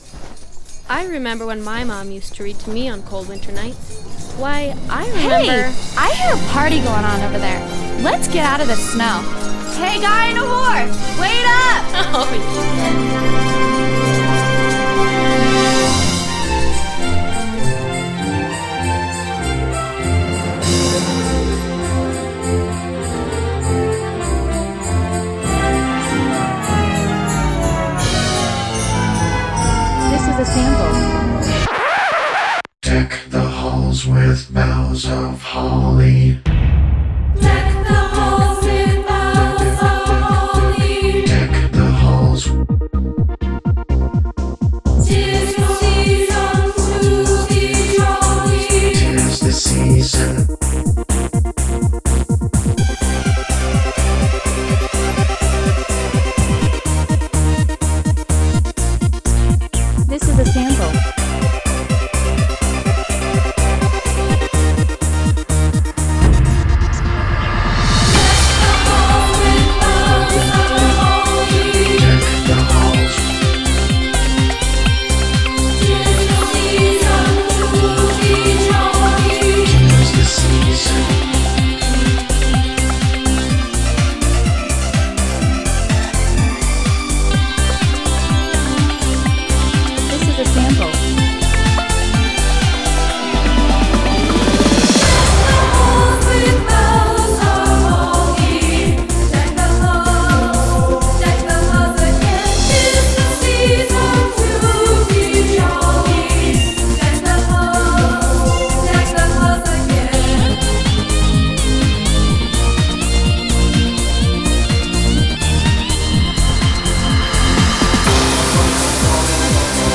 begins with the traditional sounds of Christmas